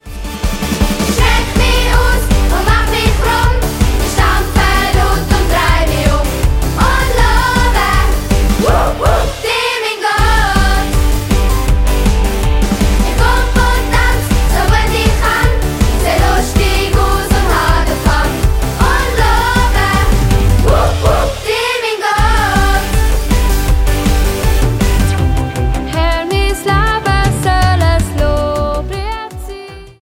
Mundartworship für Kids und Preetens